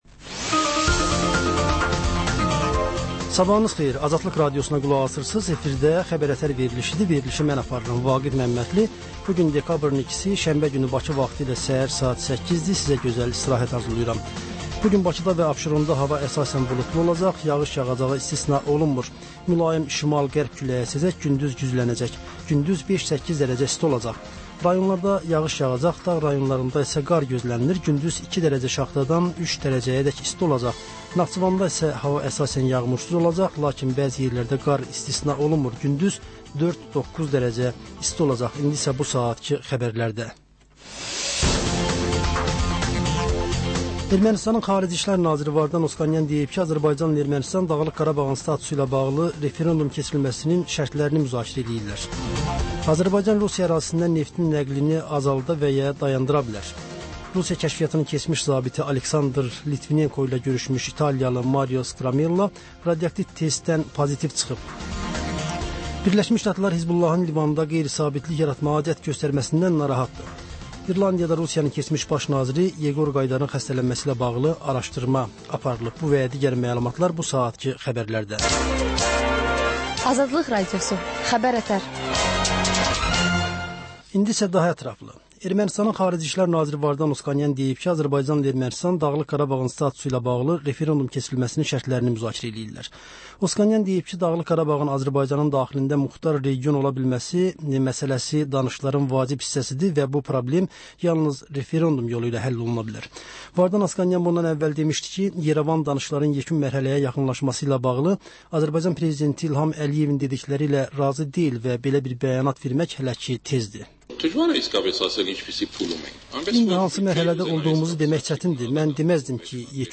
S əhər-səhər, Xəbər-ətər: xəbərlər, reportajlar, müsahibələrVə: Canlı efirdə dəyirmi masa söhbətinin təkrarı.